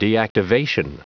Prononciation du mot : deactivation
deactivation.wav